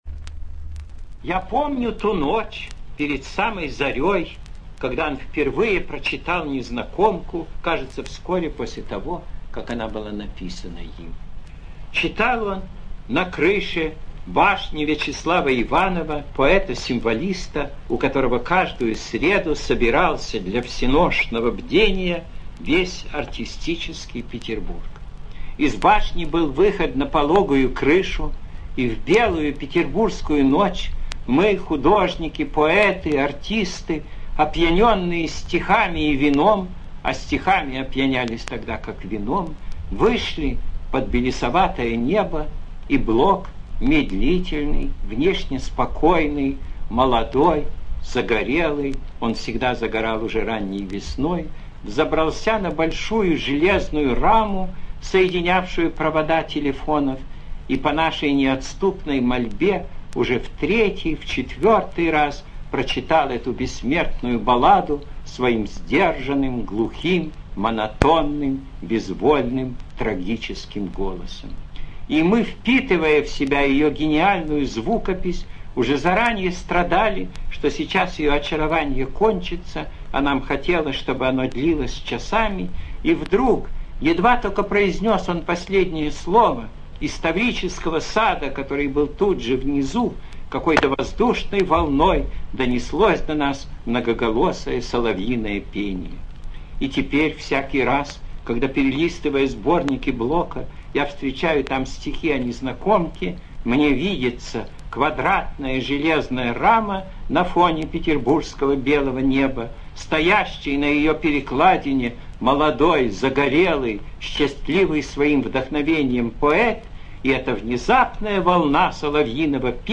ЧитаютЧуковский К., Городецкий С.
ЖанрБиографии и мемуары, Документальные фонограммы